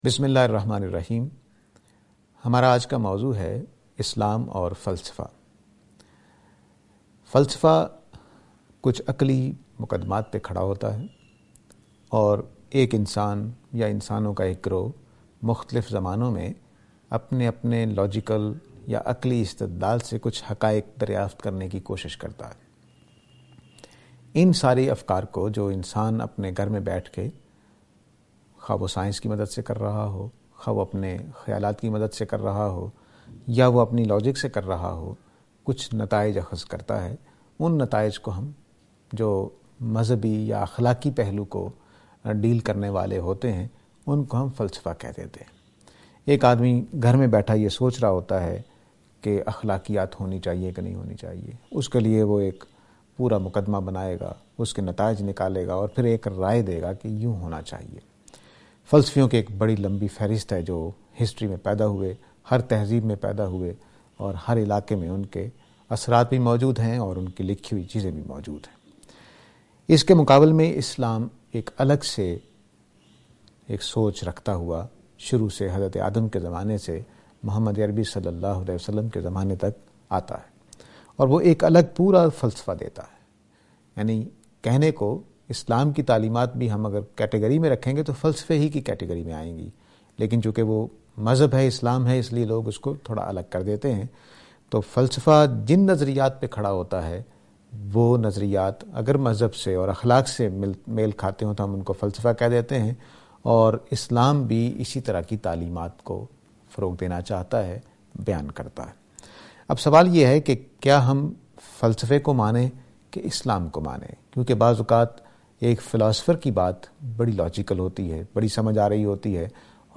This lecture is and attempt to answer the question "Philosophy and Islam".